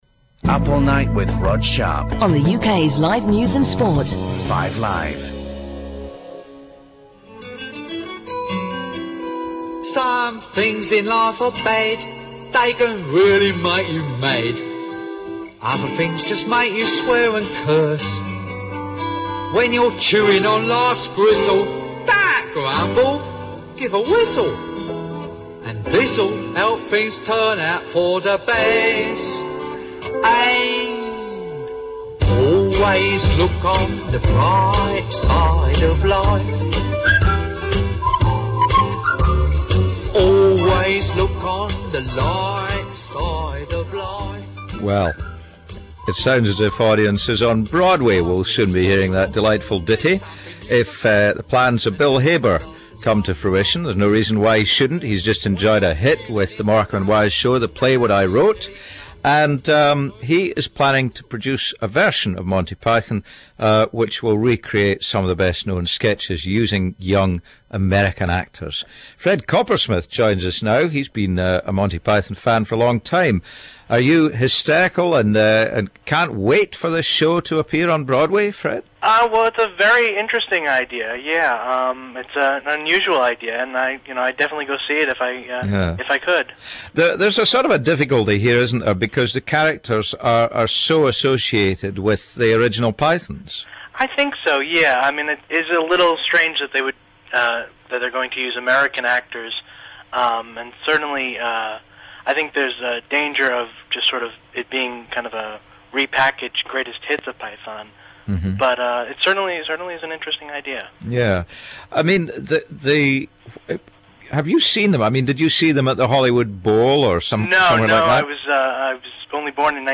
Very neat to hear your voice – funny, I always imagined you with a Canadian accent.
BBCInterview2.mp3